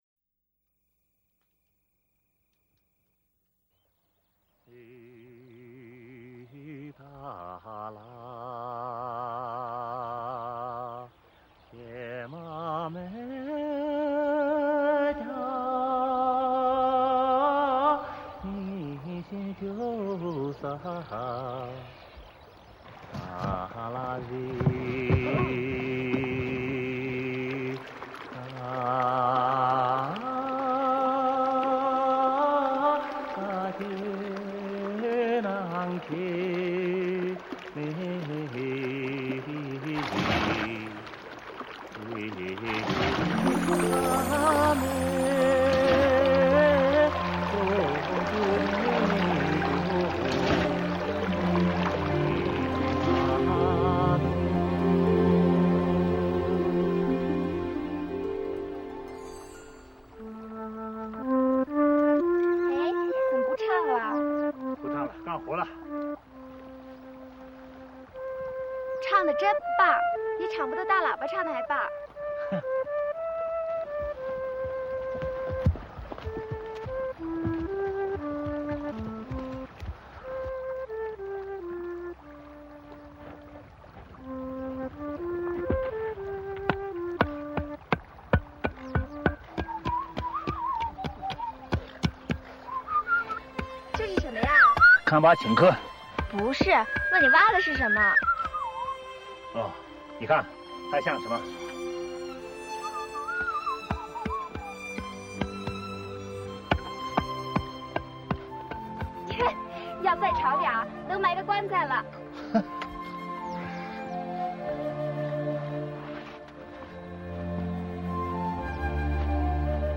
类别:电影原声
一开头是老金哼唱的蒙古腔民歌，但慢慢融进弦乐后，又转为前一曲的《幸福》主题。
而两人的对话的自在音调，也成为音乐不可或缺的一部份。